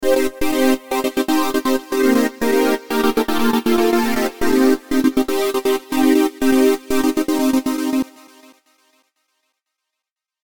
Here are a few examples of the type of effects covered in the tutorial:
Getting Clever With Your Gates: